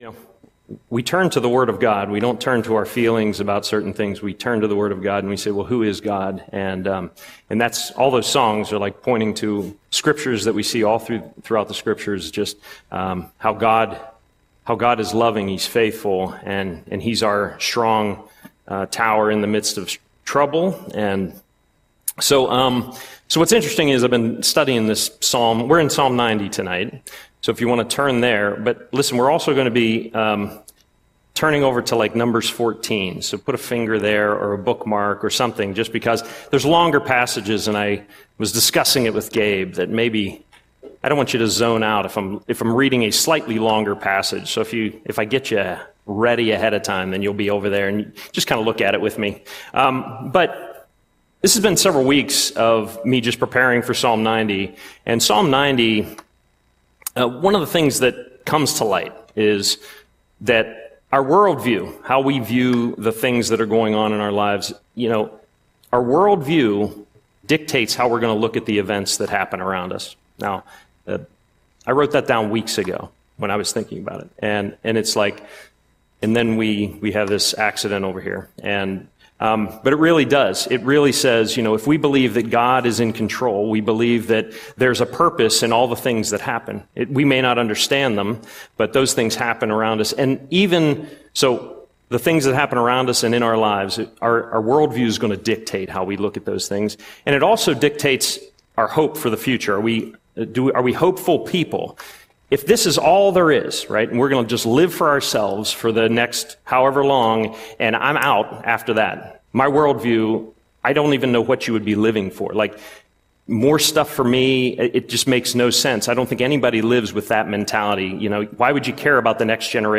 Audio Sermon - August 13, 2025